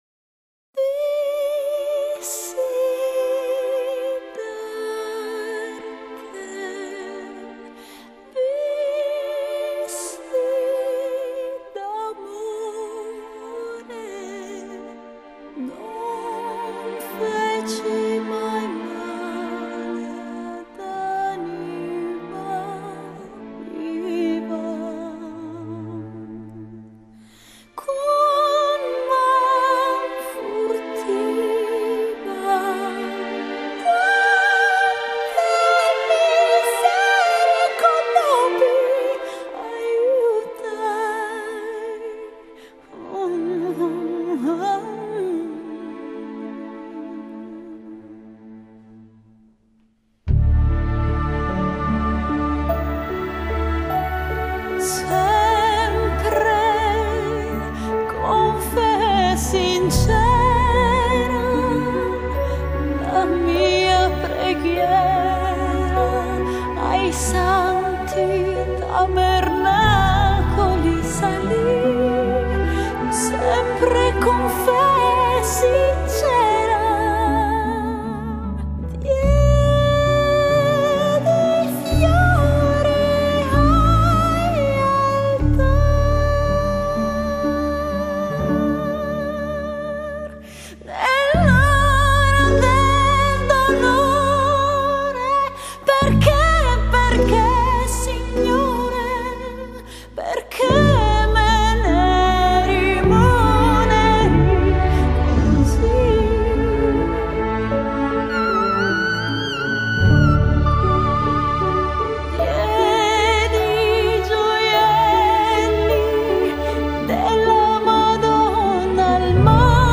金属流体 女声碟皇
最值得提示的是，这里每首曲子几乎都有弹性极饱满的低频垫底，整体平衡度好到离奇。